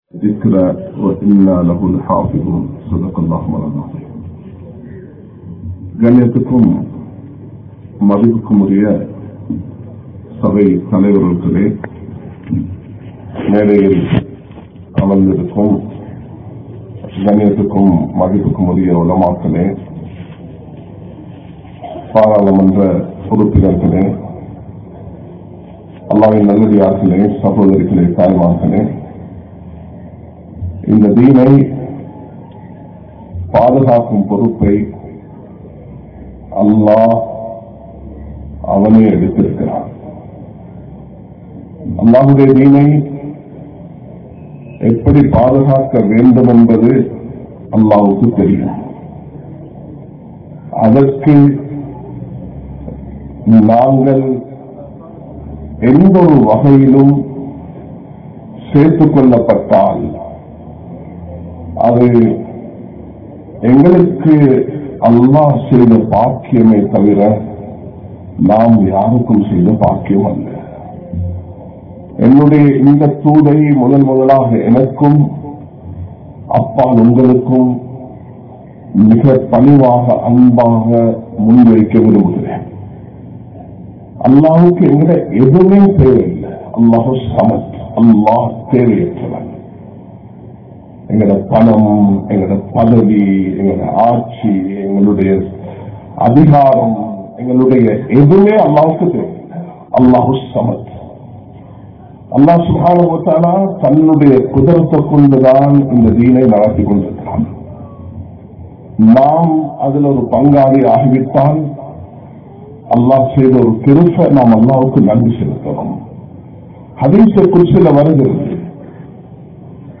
Nallavarhalaaha Vaalungal (நல்லவர்களாக வாழுங்கள்) | Audio Bayans | All Ceylon Muslim Youth Community | Addalaichenai